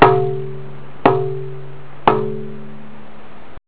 On to the Speed-Pad. Tapping it did not fill me with confidence.
Bong.